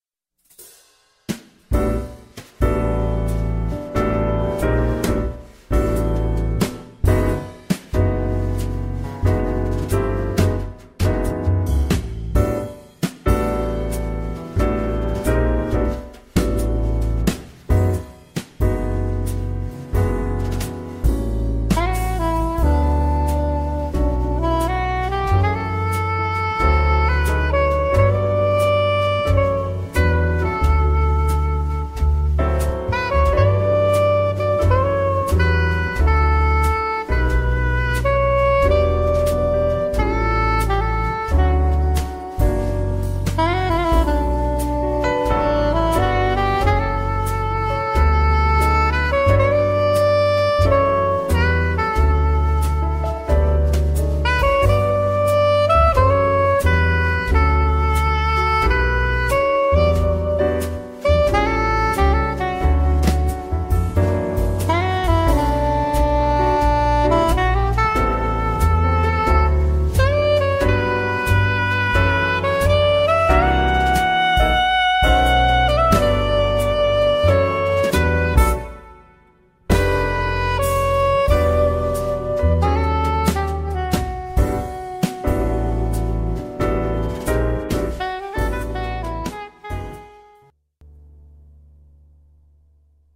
music_christmas.mp3